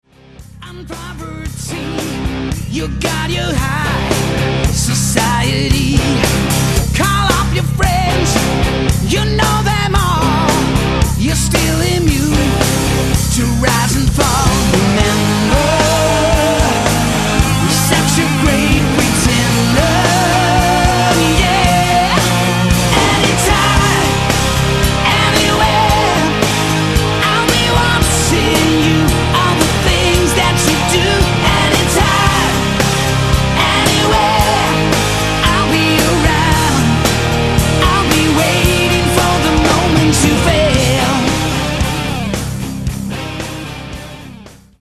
Strings,Keyboards
Percussion,Drums
« - Vocals
Guitar,Background Vocals
Bass